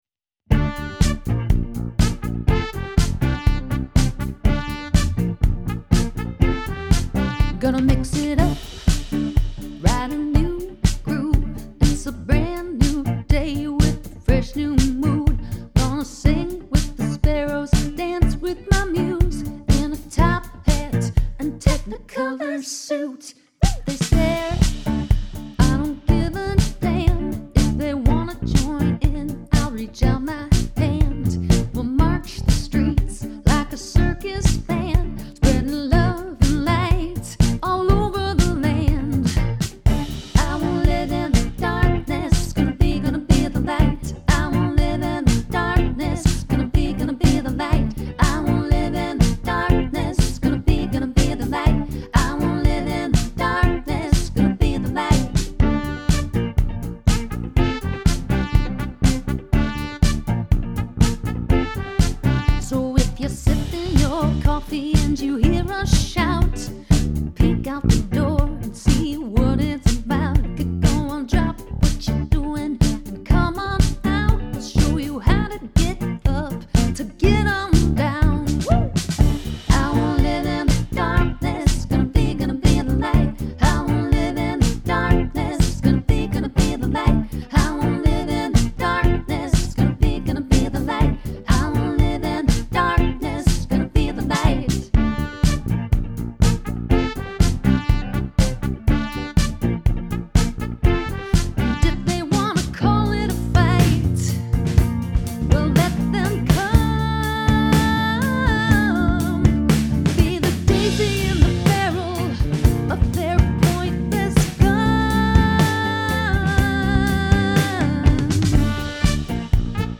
The funky upbeat feel is perfect!
Upbeat but determined.
A very groovy disco feel...with heartfelt lyrics.